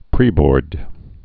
(prēbôrd)